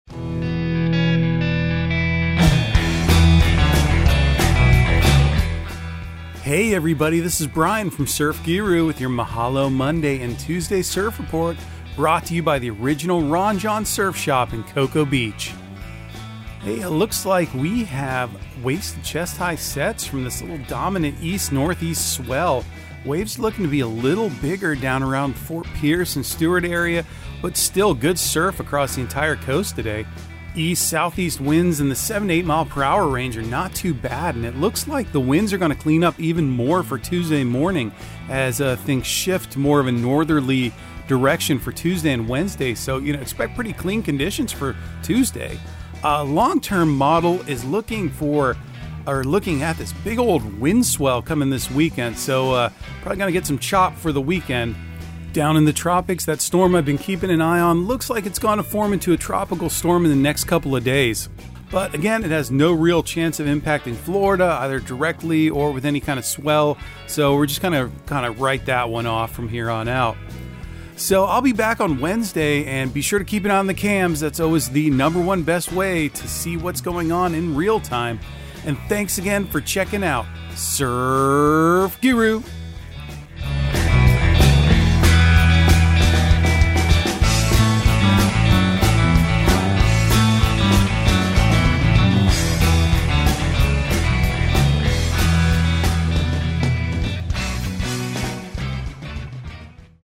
Surf Guru Surf Report and Forecast 10/31/2022 Audio surf report and surf forecast on October 31 for Central Florida and the Southeast.